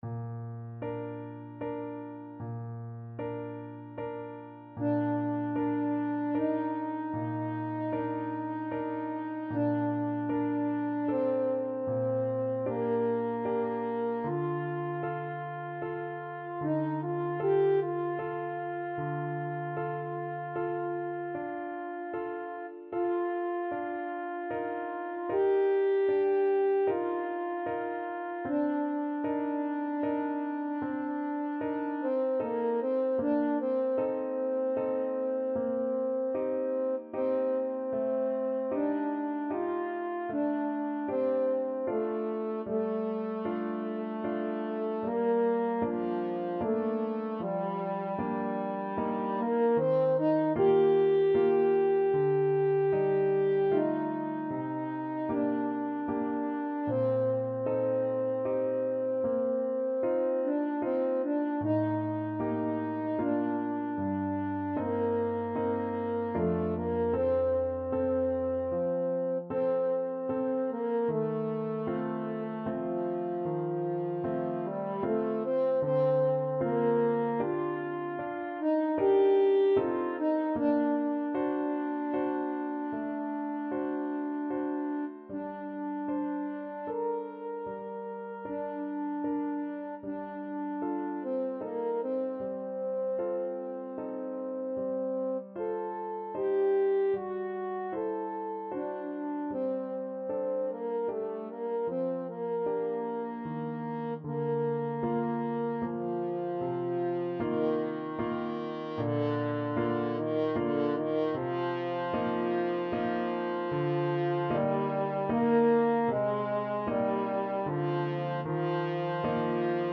Classical Ravel, Maurice Ravel Piano Concerto in G: Second Movement (Main Theme) French Horn version
French Horn
Bb major (Sounding Pitch) F major (French Horn in F) (View more Bb major Music for French Horn )
Adagio assai =76
Classical (View more Classical French Horn Music)
ravel_piano_con_2nd_mvt_HN.mp3